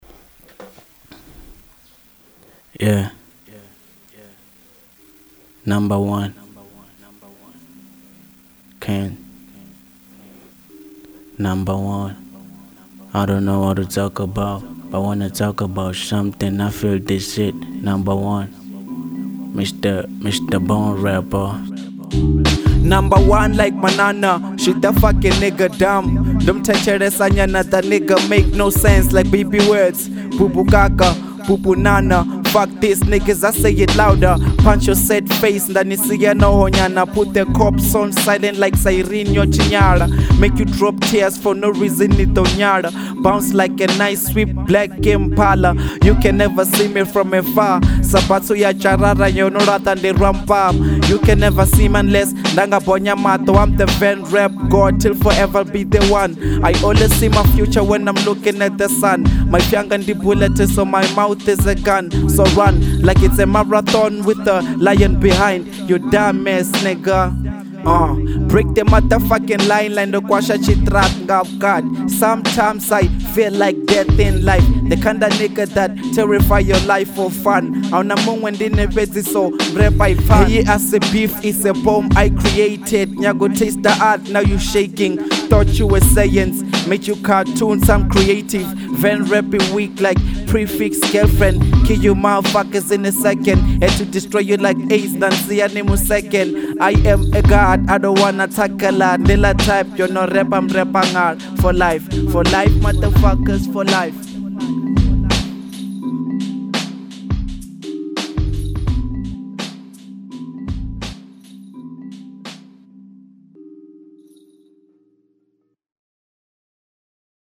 01:57 Genre : Venrap Size